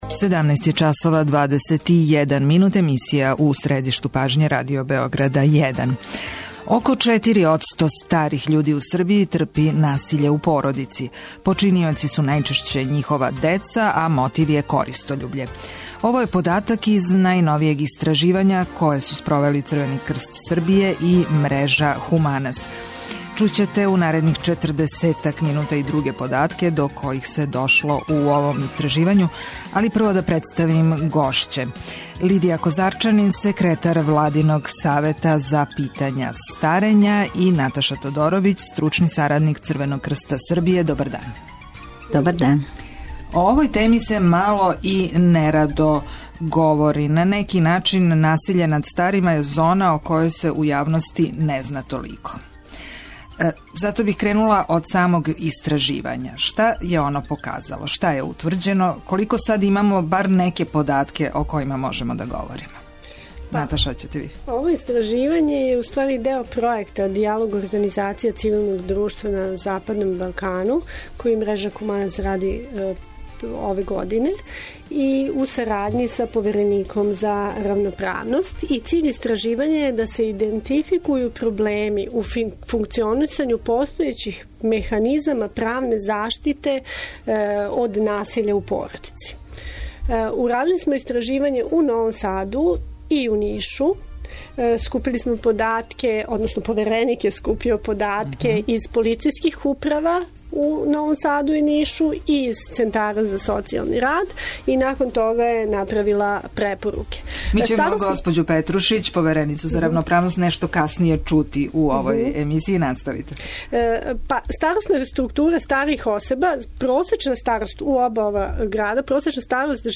Путем телефона укључићемо у разговор и Невену Петрушић, повереницу за заштиту равноправности.